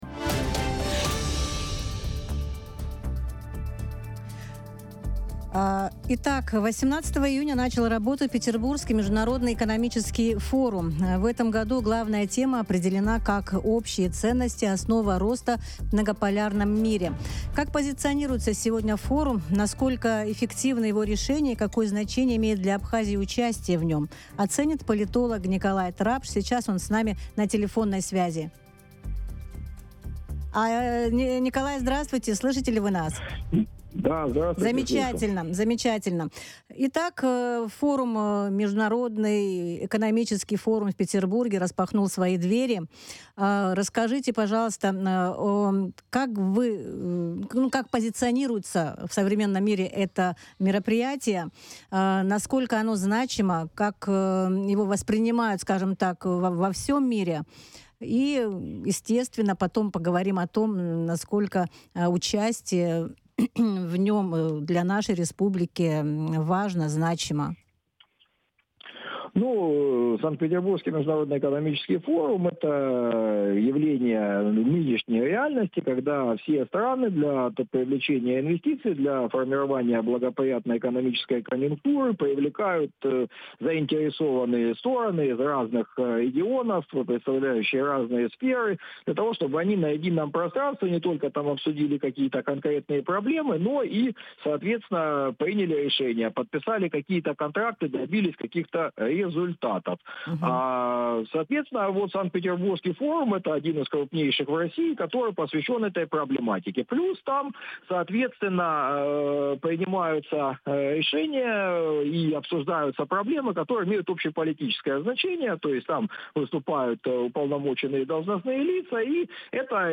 Радио
Участие Абхазии в ПМЭФ: мнение политолога